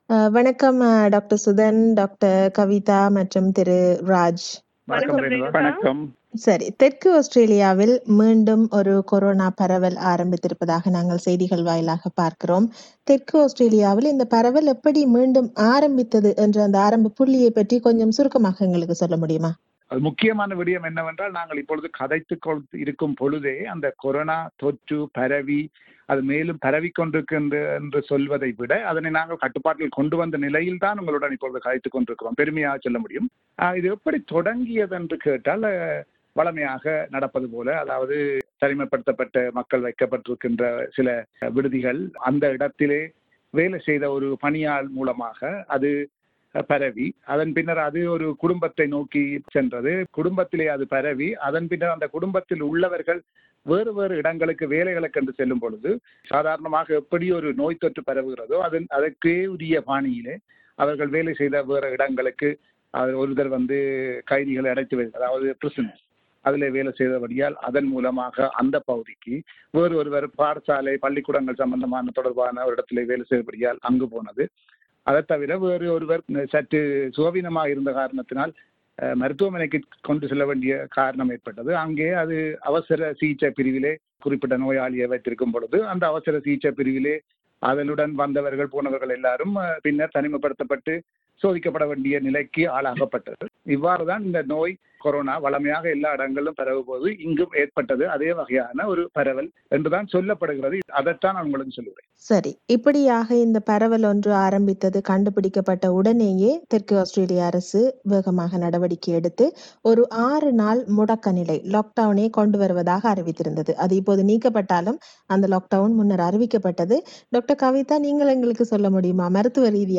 This is a panel discussion on South Australia’s COVID outbreak.